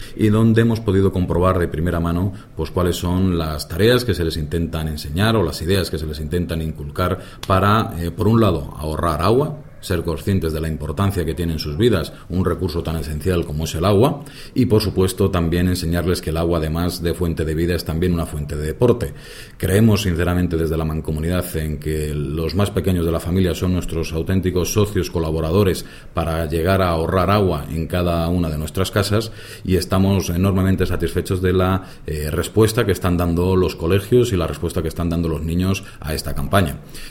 El presidente de la MAS, Jaime Carnicero, ha participado en la jornada que se ha celebrado en el colegio Alcarria de la ciudad de Guadalajara